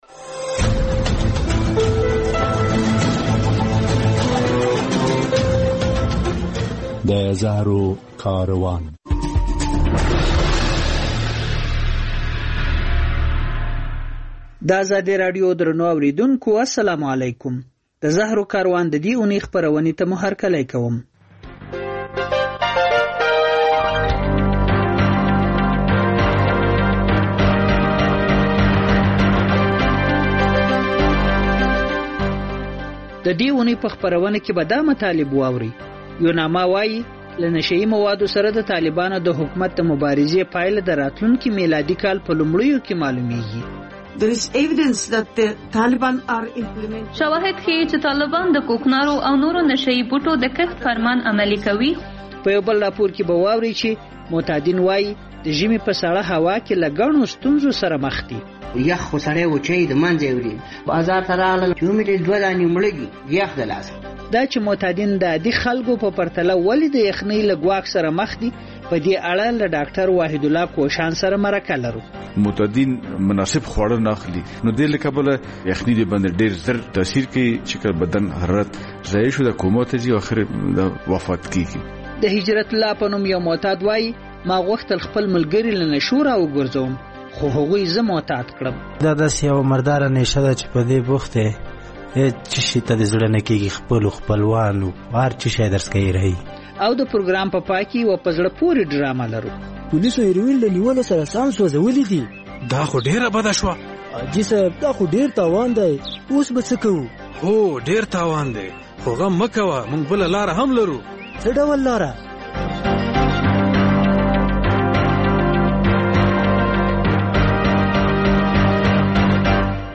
د زهرو کاروان په دې خپرونه کې به واورئ چې ملګري ملتونه وايي، راتلونکو درېیو میاشتو کې به معلومه شي چې په افغانستان کې د کوکنارو د کښت مخنیوي برخه کې د طالبانو اقدام څومره نتیجه ورکړې ده. په خپرونه کې له کابله راپور اورئ چې د ژمي په رارسېدو معتادین د مرګ له ګواښ سره مخ دي.